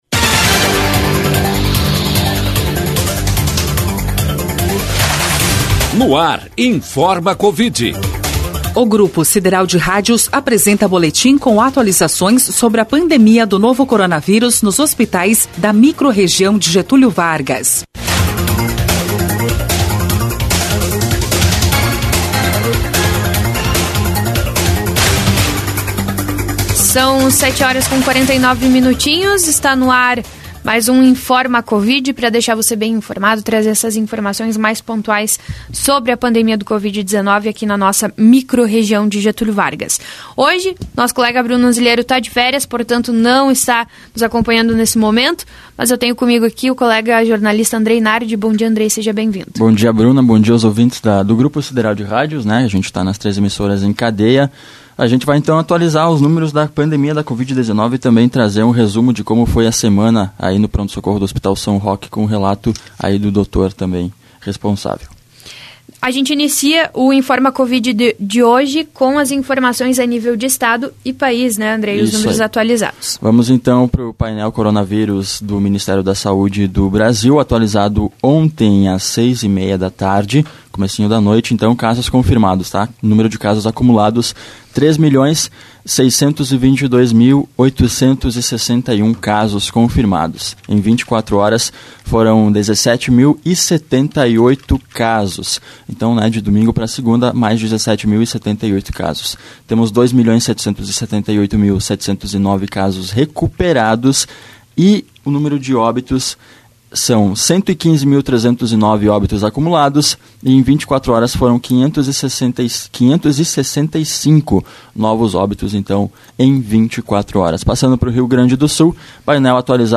Todas as terças-feiras, às 7h45min, o Grupo Sideral de Rádios veicula o Informa Covid, boletim informativo com atualizações sobre a pandemia do novo coronavírus na microrregião de Getúlio Vargas.